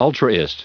Prononciation du mot ultraist en anglais (fichier audio)
Prononciation du mot : ultraist